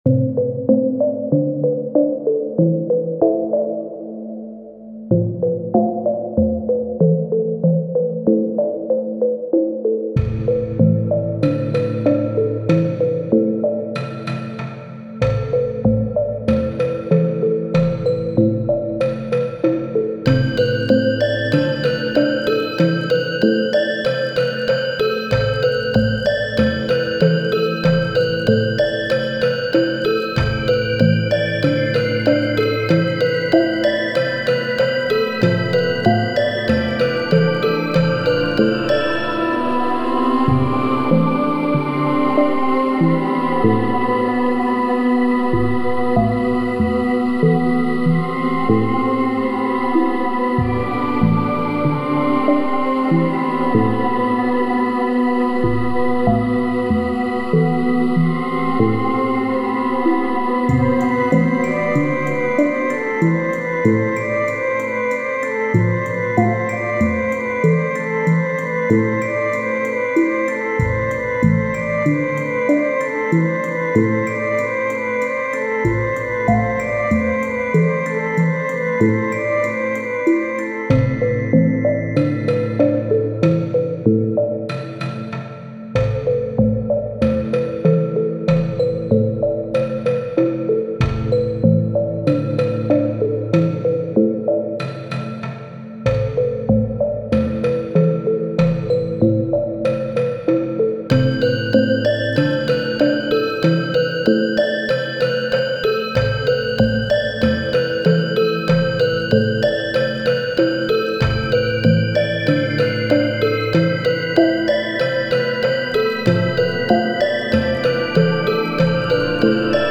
幽霊×ほのぼの系のBGM。 残響などのサウンドはホラー寄りにして、作編曲面でほのぼのした雰囲気を狙って混ぜている。